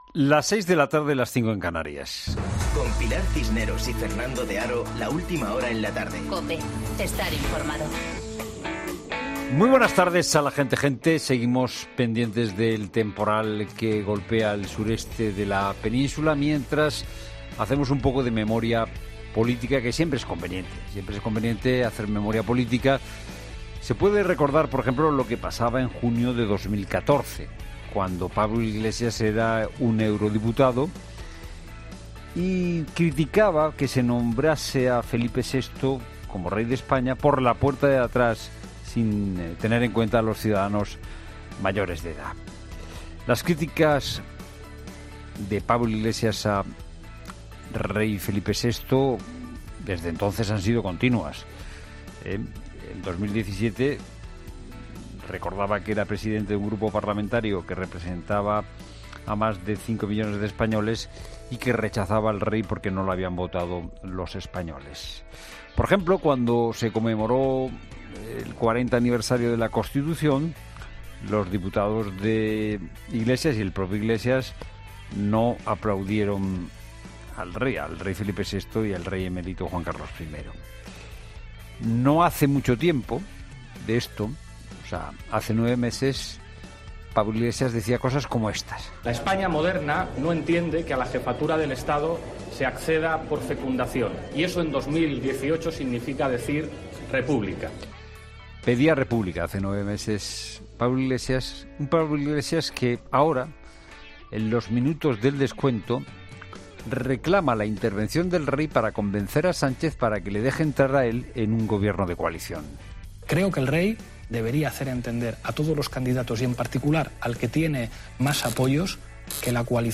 Monólogo de Fernando de Haro